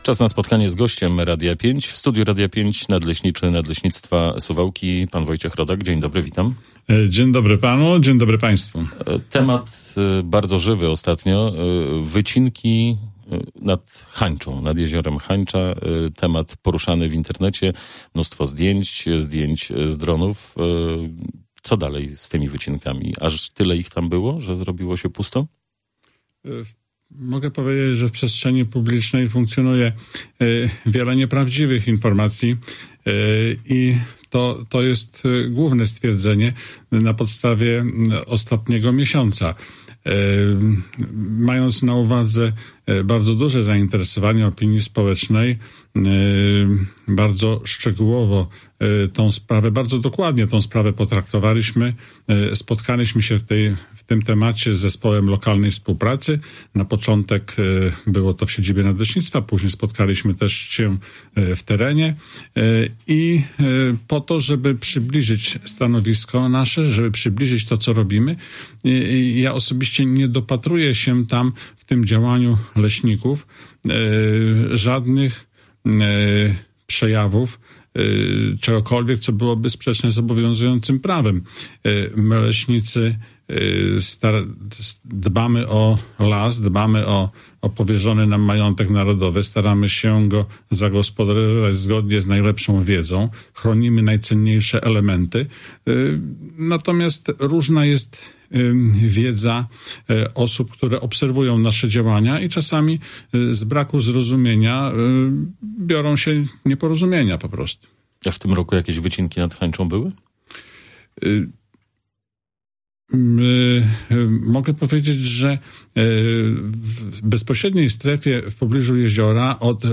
Audycja „Gość Radia 5”: